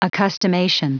Prononciation du mot accustomation en anglais (fichier audio)
accustomation.wav